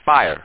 1 channel
p_fire.mp3